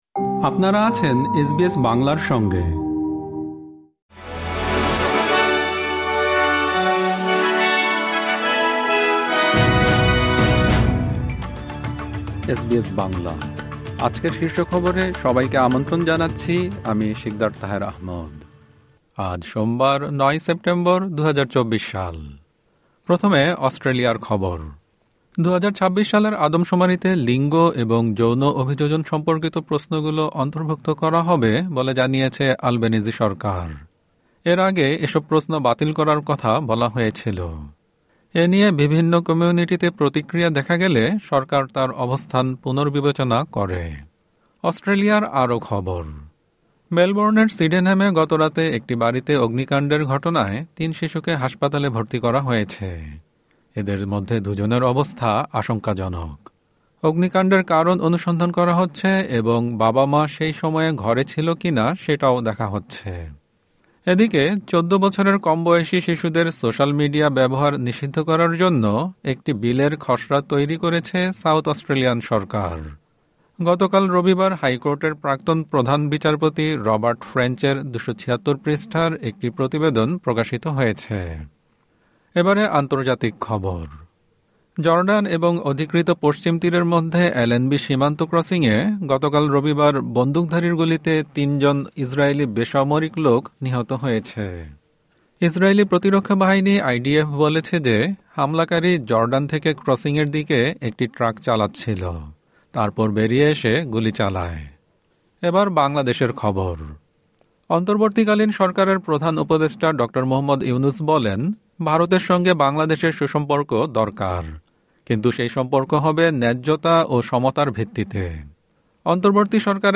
এসবিএস বাংলা শীর্ষ খবর: ৯ সেপ্টেম্বর, ২০২৪